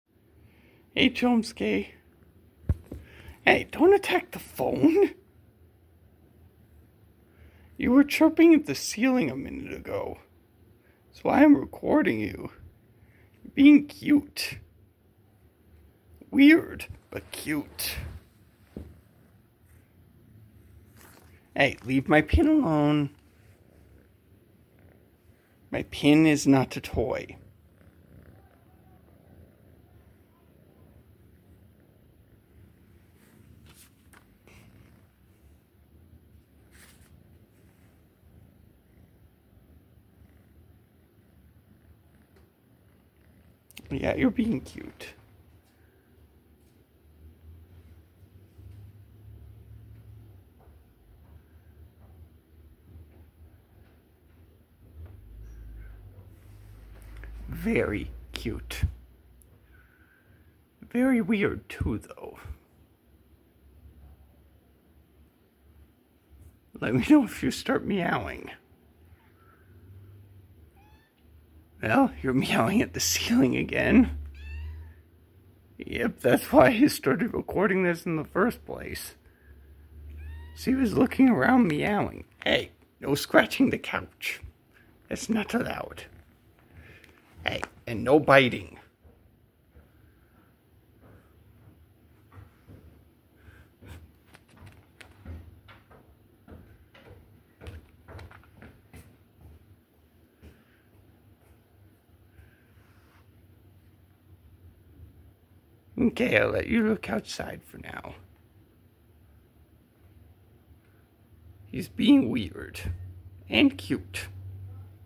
A video of my cat Chomusuke